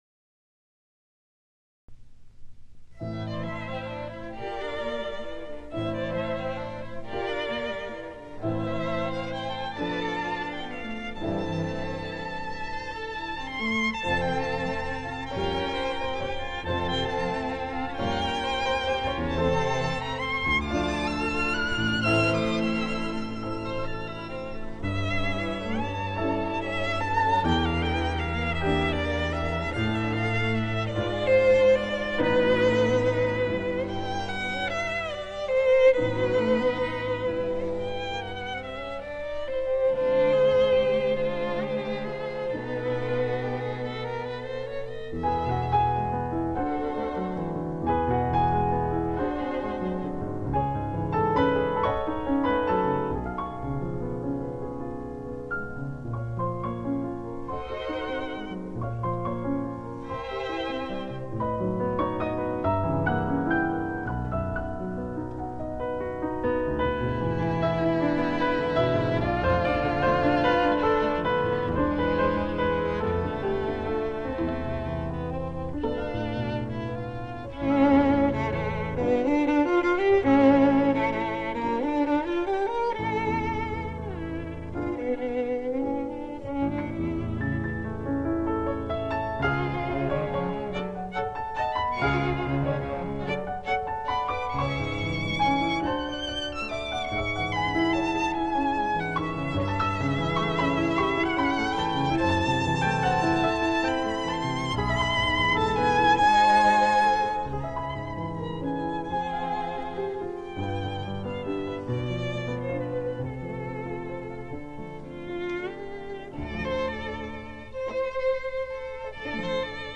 喬桑只活了四十四年, 從律師到作曲家, 也只有十九年的時間從事創作, 他一生只有三十九首作品, 法國樂界評為赤子之作, 品質甚高, 真實而不造作, 足以代表法國浪漫派的曲風, 他的作品經常在室內樂音樂會演出.
此處分享第二十一號作品D大調鋼琴與弦樂六重奏第二樂章.